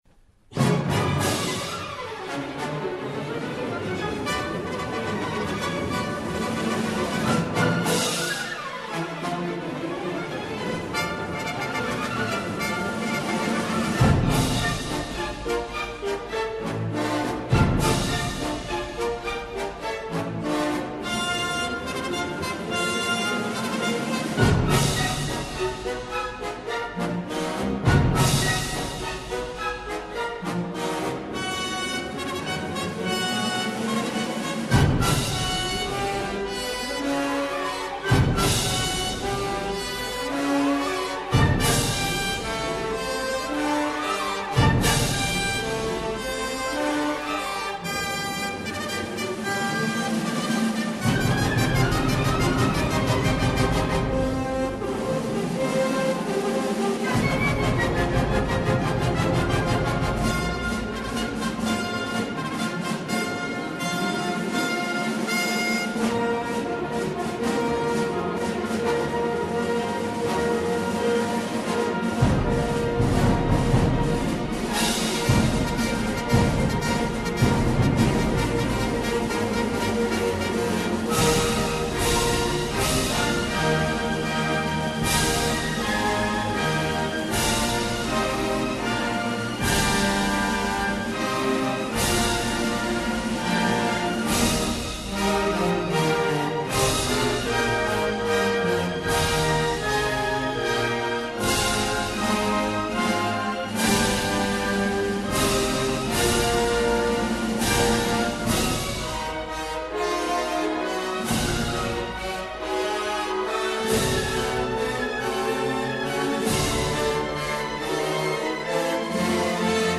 файл) 4,85 Мб Чайковский П.И. Опера «Мазепа» (Симфонический антракт «Полтавский бой») 1